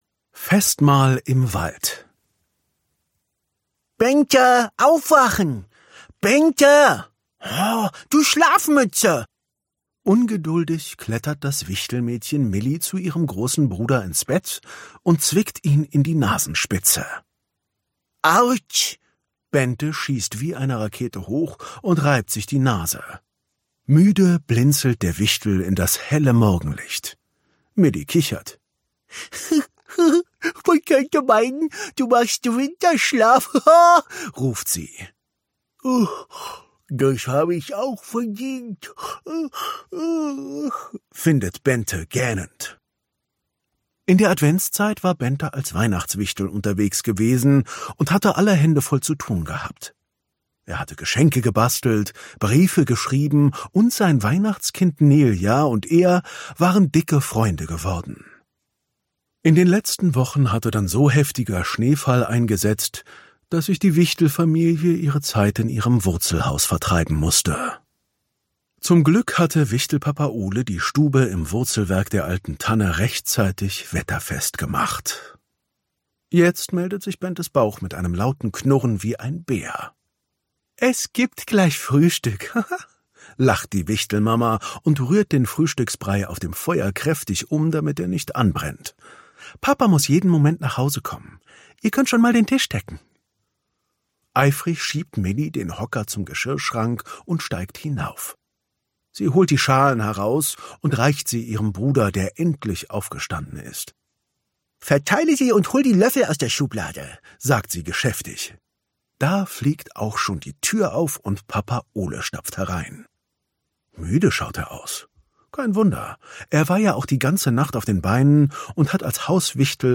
Steffen Groth (Sprecher)
Ungekürzte Lesung